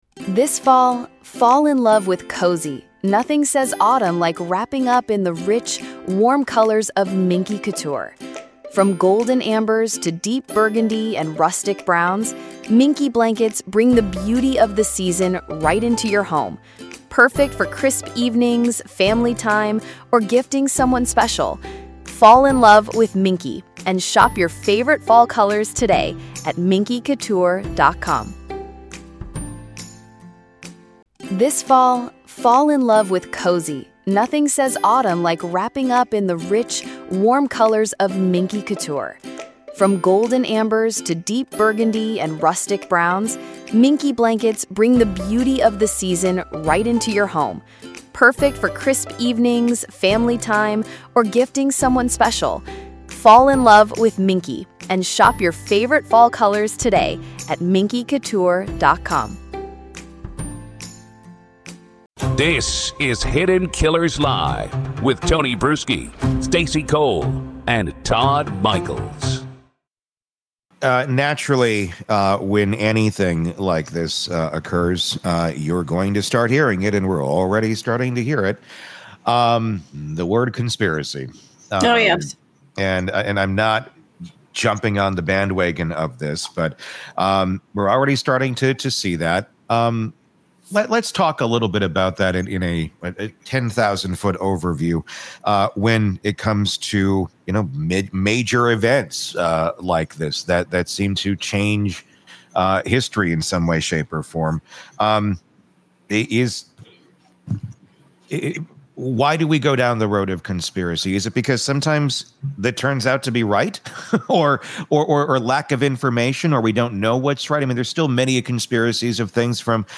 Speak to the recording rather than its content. The episode closes with the FBI’s press conference, revealing new investigative details — but the focus remains on the psychology of coping, control, and collective anxiety when violence shatters the national psyche.